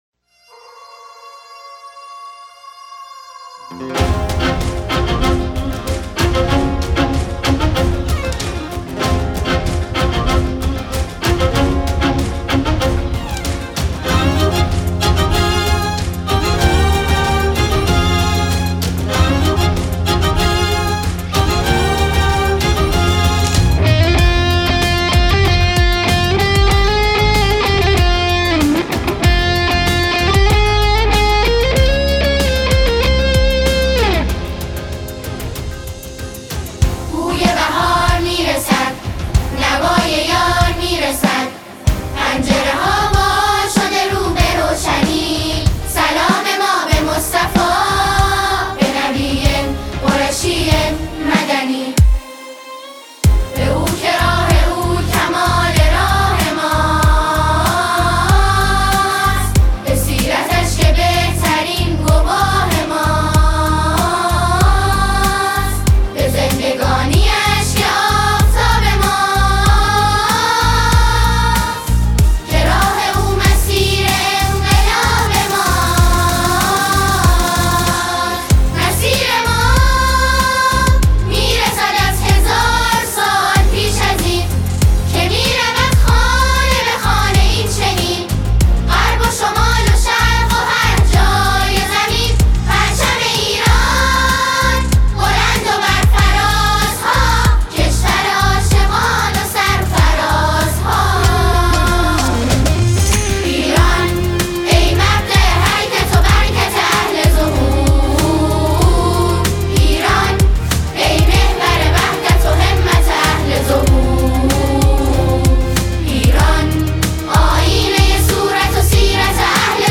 با اجرای گروه سرود مهر سوگند